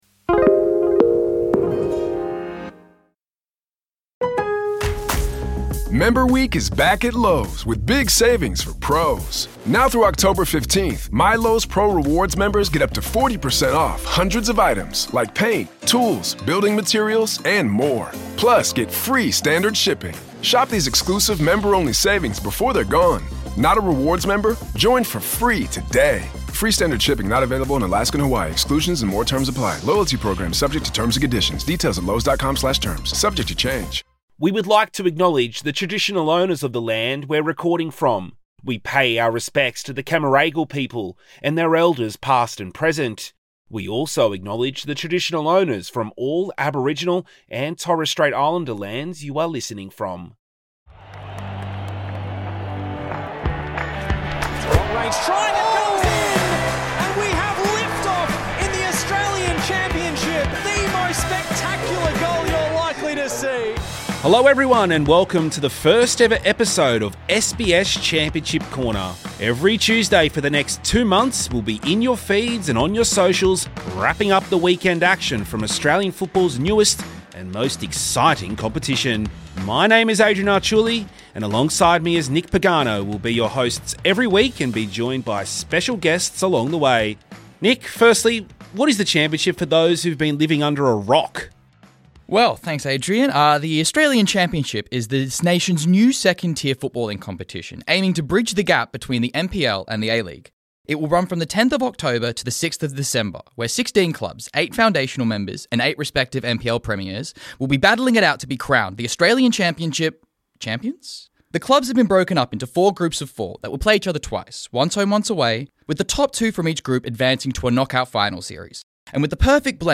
سنجیدہ تجزیے، حقیقی کہانیاں، اور دلچسپ گفتگو کا امتزاج پیش کیا گیا ہے۔